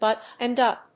アクセントbúttoned úp
音節bùttoned úp